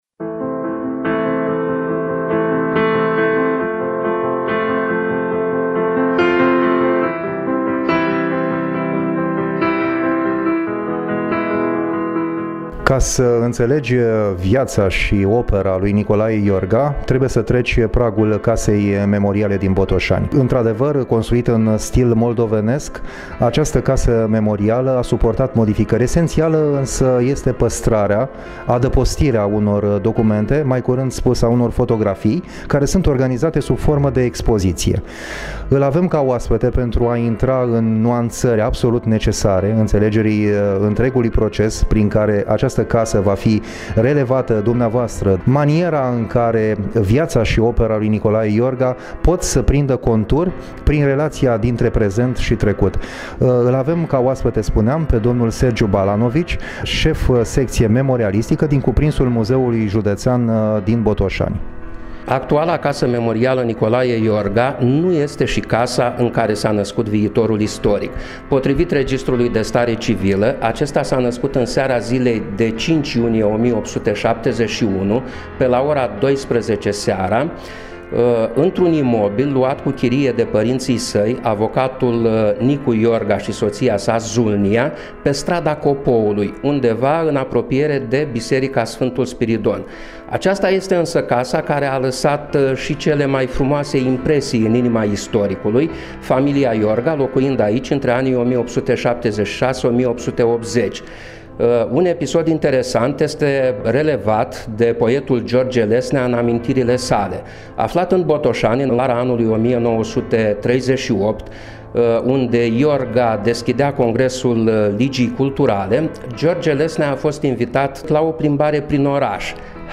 Interviu (audio)